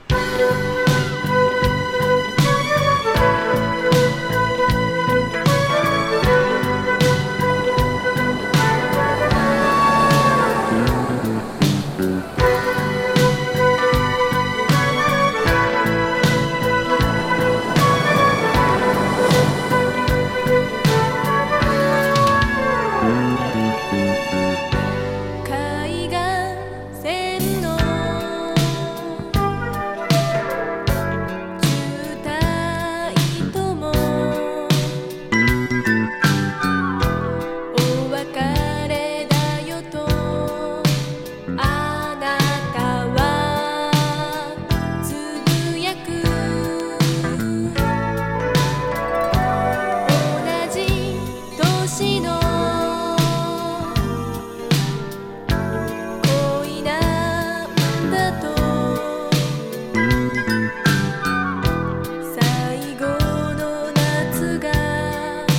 ファンカラティーナ・チューン